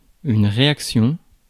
Ääntäminen
Synonyymit accommodation Ääntäminen France: IPA: /ʁe.ak.sjɔ̃/ Haettu sana löytyi näillä lähdekielillä: ranska Käännöksiä ei löytynyt valitulle kohdekielelle.